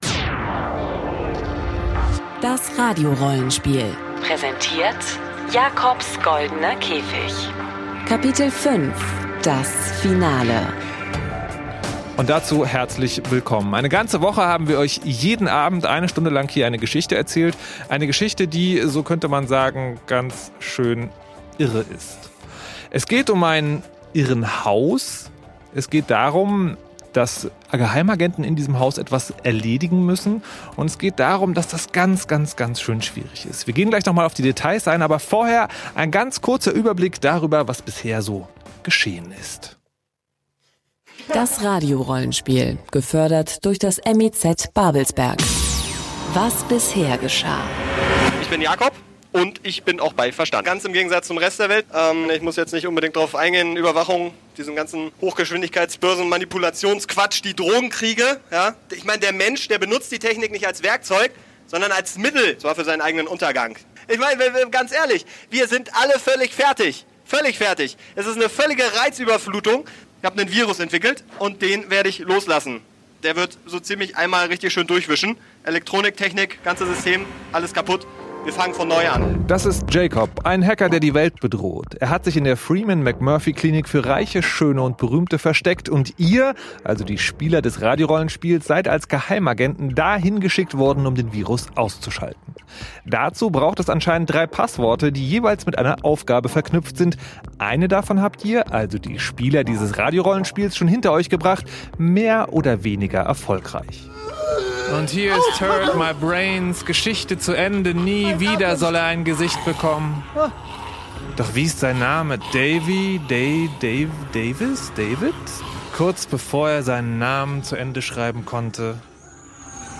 Hörspiele im Radio.